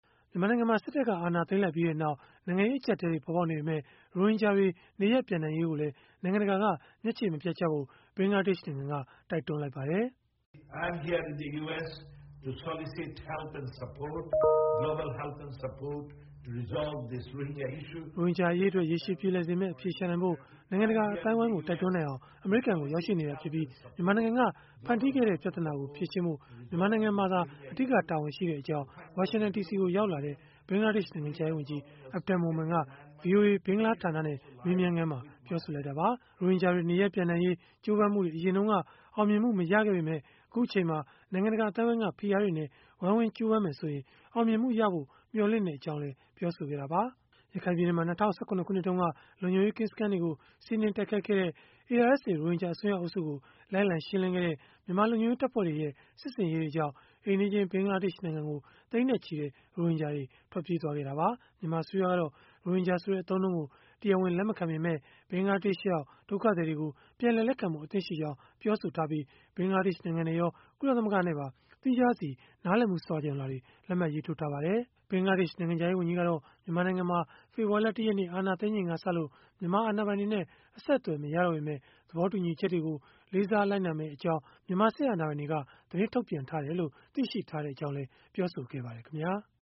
“ရိုဟင်ဂျာအရေးအတွက် ရေရှည်ပြေလည်စေမယ့် အဖြေရှာနိုင်ဖို့ အမေရိကန်ကို ရောက်ရှိနေတာဖြစ်ပြီး၊ မြန်မာနိုင်ငံက ဖန်တီးခဲ့တဲ့ ပြဿနာကို ဖြေရှင်းဖို့ မြန်မာနိုင်ငံမှာ အဓိက တာဝန်ရှိကြောင်း”ဝါရှင်တန်ဒီစီကို ရောက်လာတဲ့ ဘင်္ဂလားဒေ့ရှ် နိုင်ငံခြားရေးဝန်ကြီး Abdul Momen က ဗွီအိုအေ ဘင်္ဂလားဌာနနဲ့ မေးမြန်းခန်းမှာ ပြောဆိုလိုက်တာပါ။